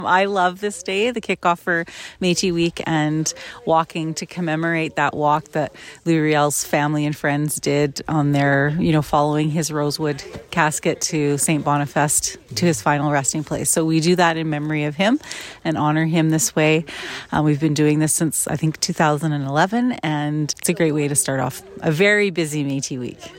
Metis Nation Within Alberta President Andrea Sandmaier spoke with CFWE, saying she loves this day to commemorate the Louis Riel walk that his family and friends did following his death, along with speaking on a busy Metis Week to follow.